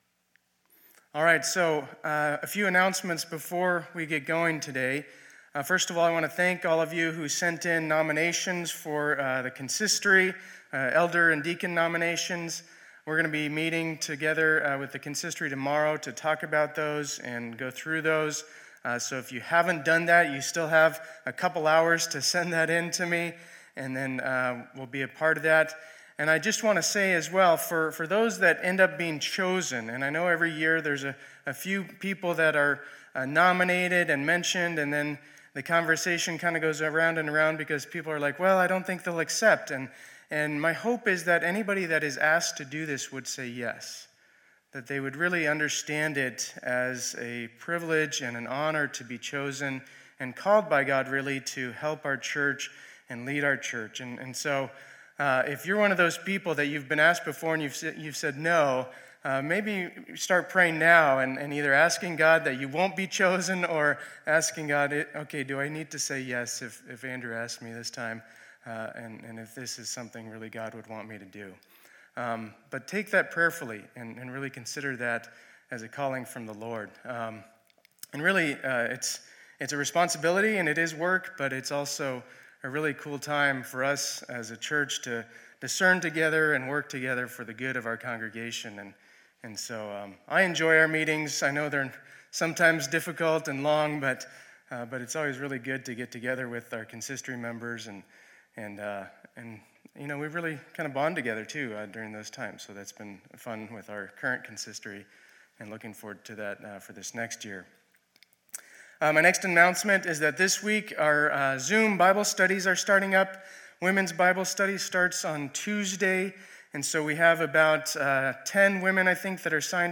2020-08-20 Sunday Service